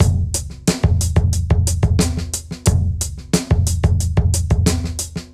Index of /musicradar/sampled-funk-soul-samples/90bpm/Beats
SSF_DrumsProc1_90-04.wav